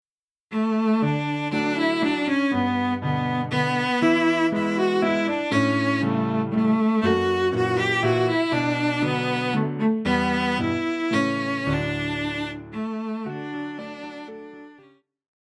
古典
大提琴
鋼琴
聖誕歌曲,童謠,傳統歌曲／民謠
演奏曲
獨奏與伴奏
有節拍器